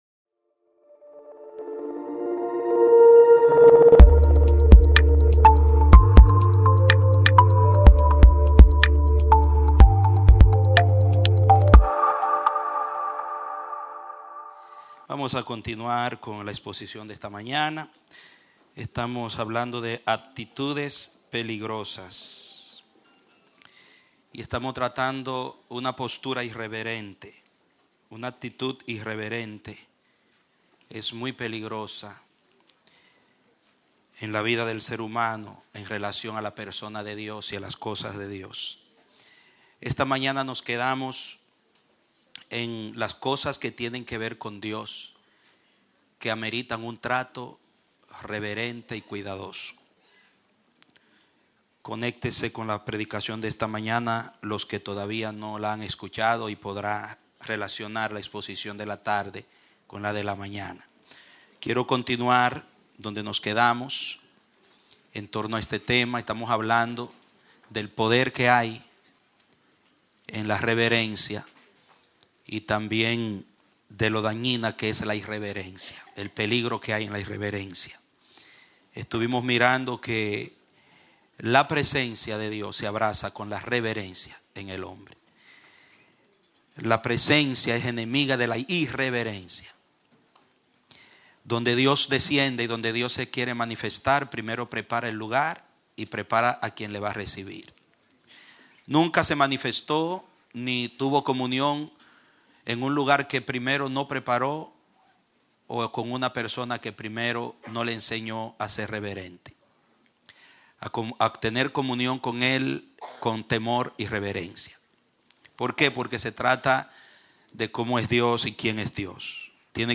Clase Bautismal – El Amanecer de la Esperanza Ministry